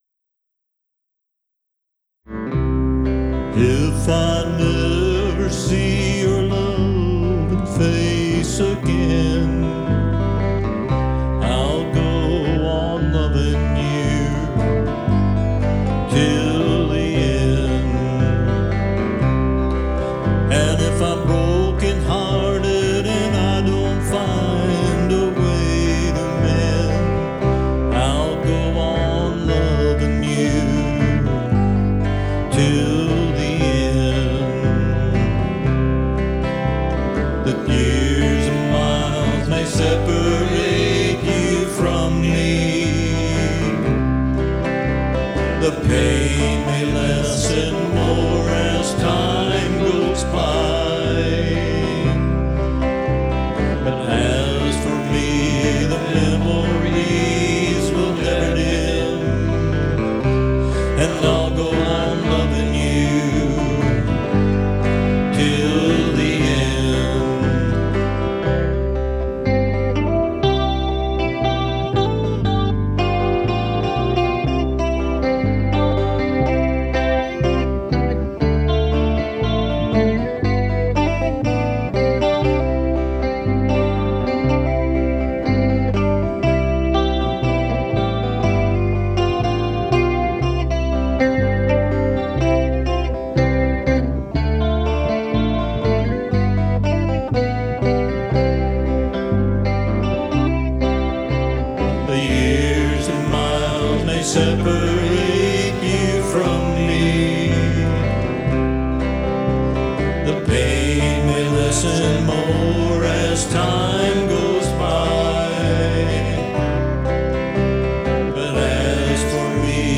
Lap Steel